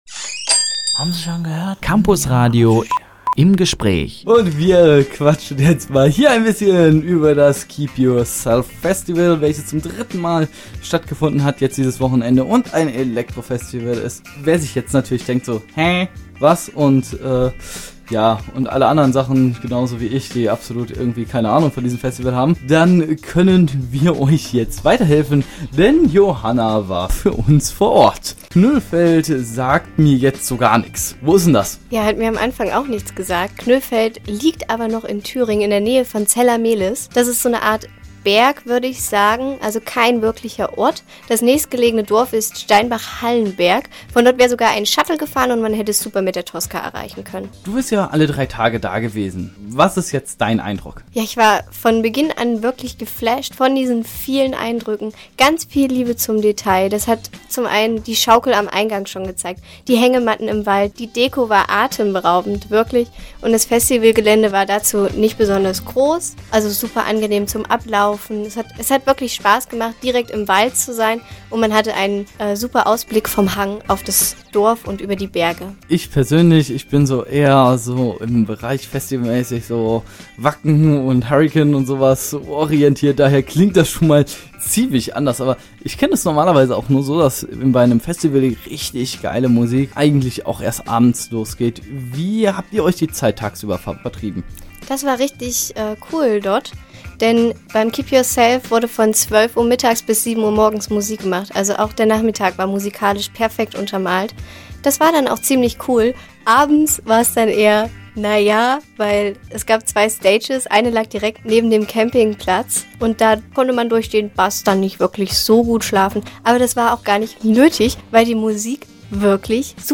Im Gespräch: Keep Yourself Festival – Campusradio Jena